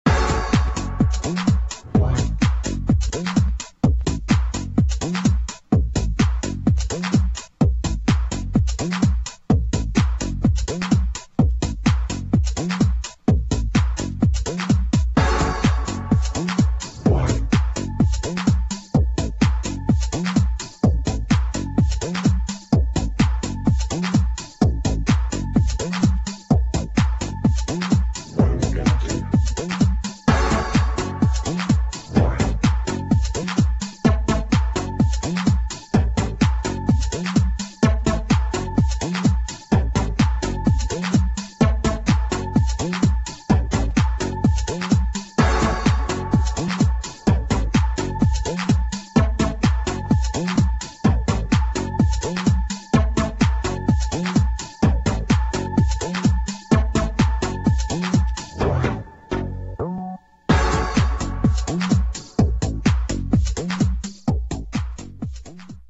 [ TECHNO | PROGRESSIVE HOUSE ]